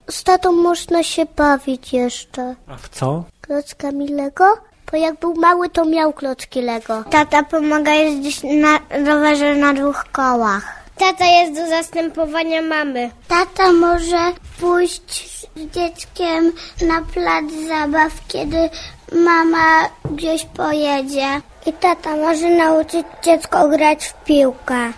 klon6g4rx2med43_z-dziecmi-o-tacie.mp3